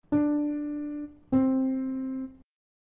المسافات الصوتية الثنائية
استمع إلى المسافات الثنائية التالية ثم حدد إن كانت صاعدة أو نازلة